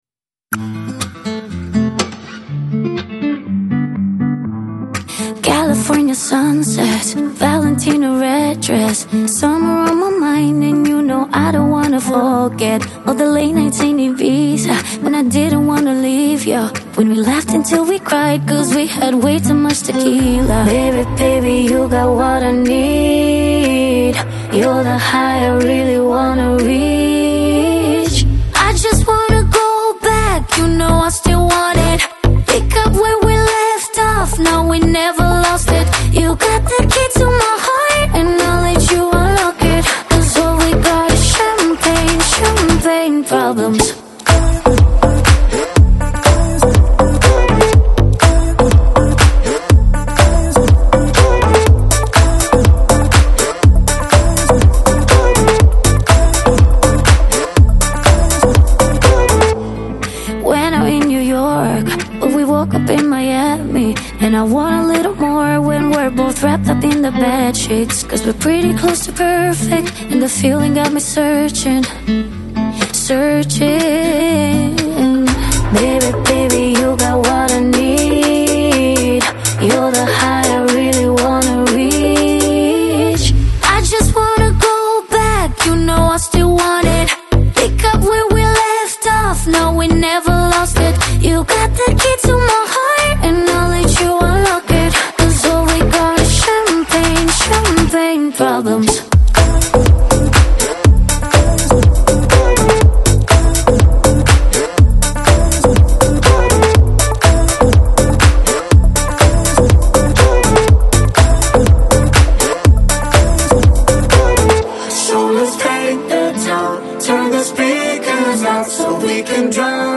Жанр: Pop, Dance